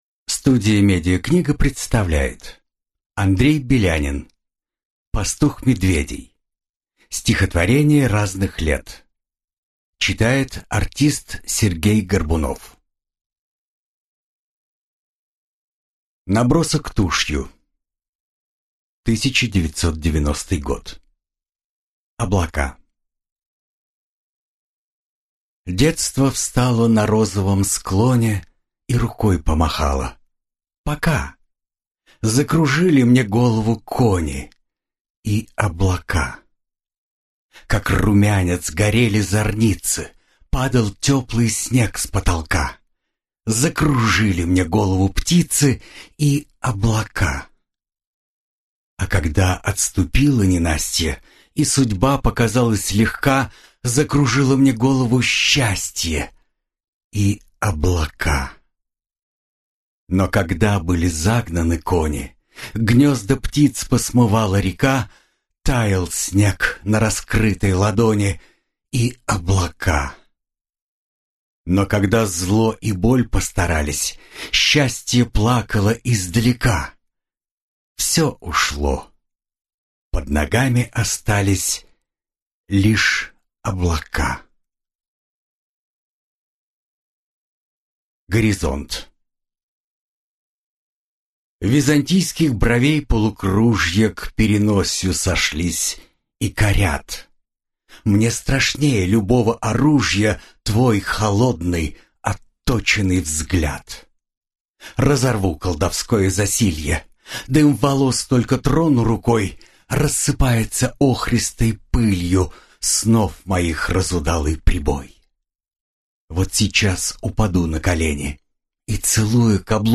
Аудиокнига Пастух медведей (сборник)